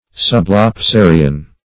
Search Result for " sublapsarian" : The Collaborative International Dictionary of English v.0.48: Sublapsarian \Sub`lap*sa"ri*an\, n. & a. [Pref. sub + lapse: cf. F. sublapsarien, sublapsarie.]
sublapsarian.mp3